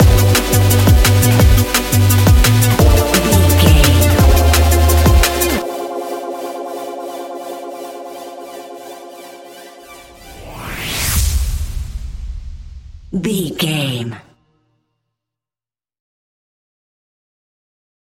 Ionian/Major
F♯
electronic
techno
trance
synths
synthwave
instrumentals